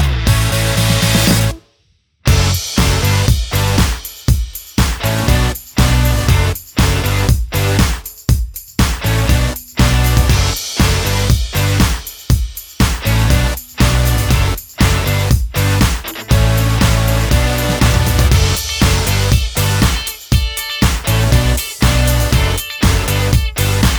Minus Main Guitars For Guitarists 3:30 Buy £1.50